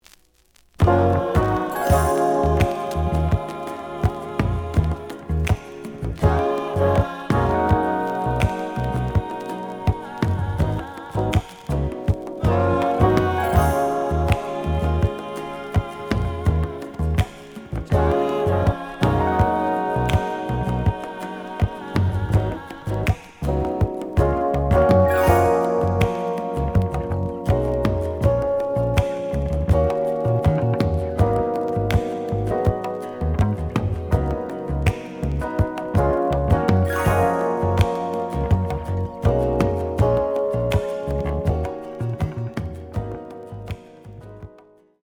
(Instrumental)
The audio sample is recorded from the actual item.
●Genre: Soul, 80's / 90's Soul